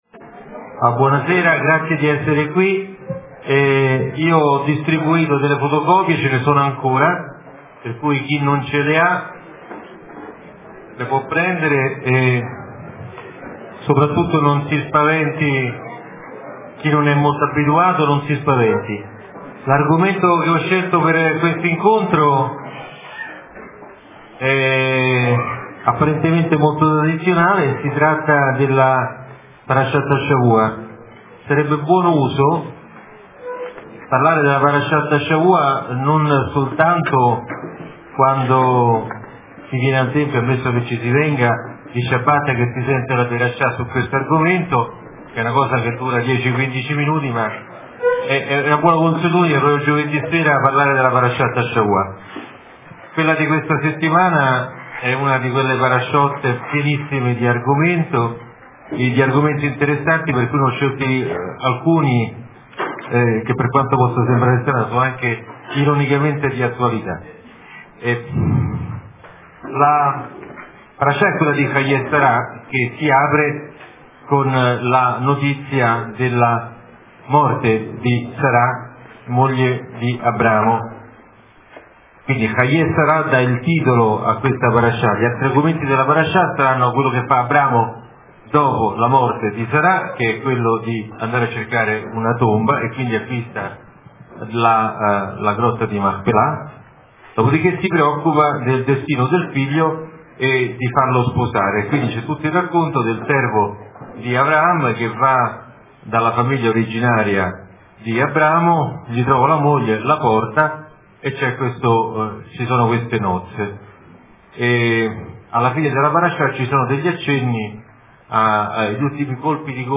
registrato da Le fonti Il testo della parash� Ascolta Rav Riccardo Di Segni Commento alla Parash� di Haii� Sar� Lezione tenuta al �Pitigliani� di Roma in occasione del 60� compleanno del Rav. 12 Novembre 2009